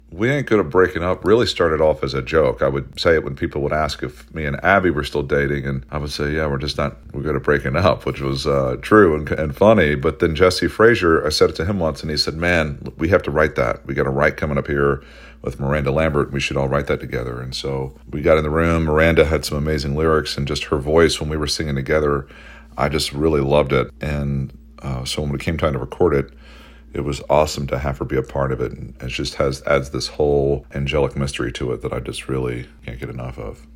Brothers Osborne's TJ Osborne talks about the idea behind the song "We Ain't Good At Breaking Up."